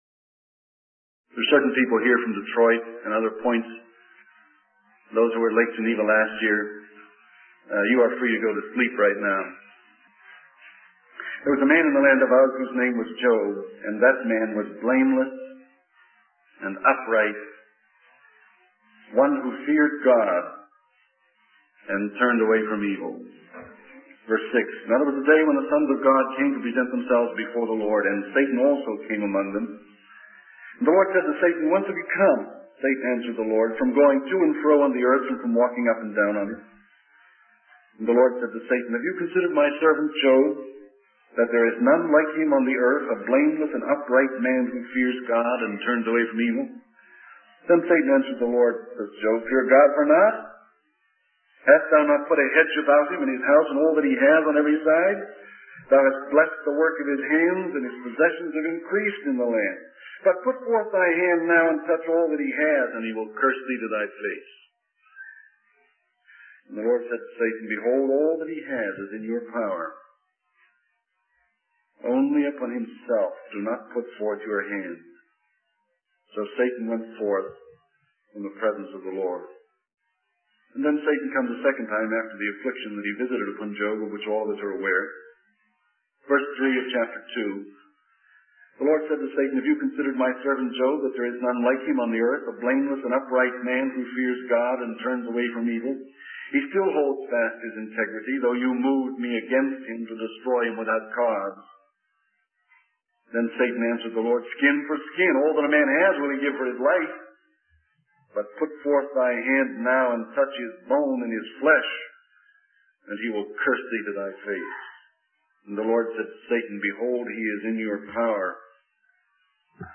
In this sermon, the speaker discusses the limited knowledge and understanding that humans have in the grand scheme of God's sovereignty. He uses the analogy of actors on a stage, emphasizing that we only have a limited perspective of the scenes that have come before and will come after our own.